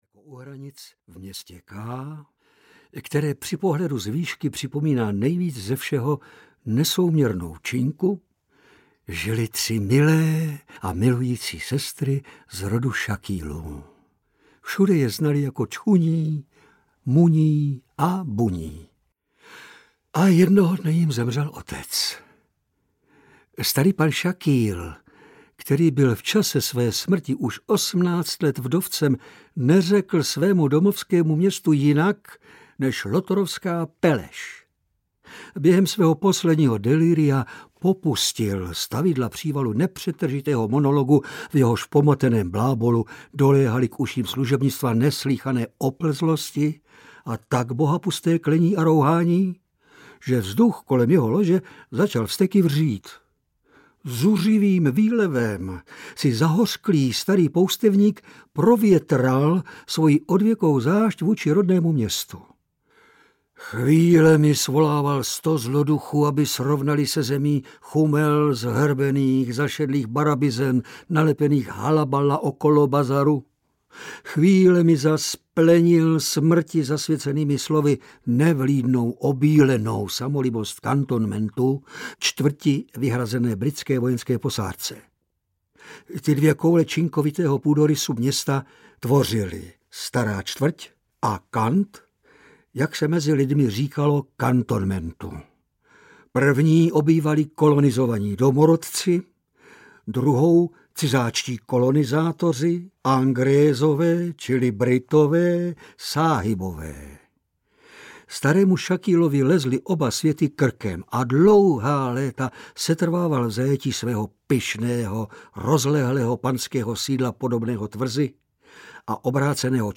Hanba audiokniha
Ukázka z knihy